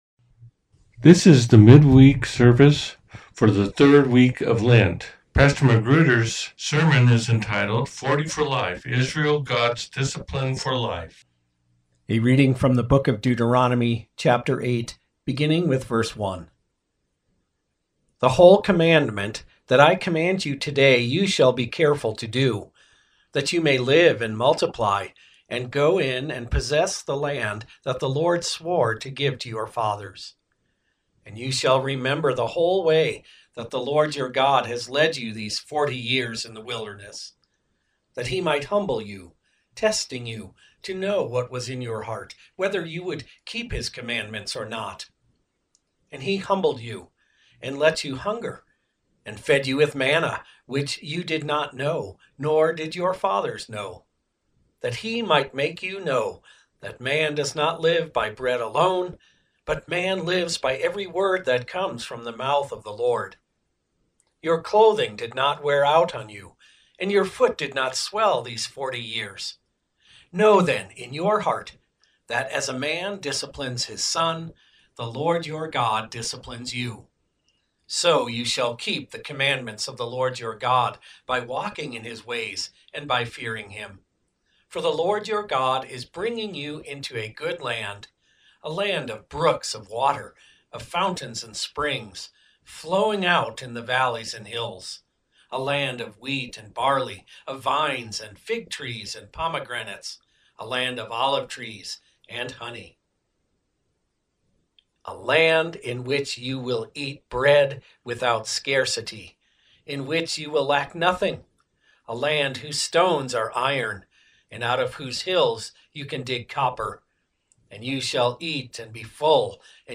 40 For Life: Israel, God's Discipline For Life - Sermon At Peace With Christ Lutheran Church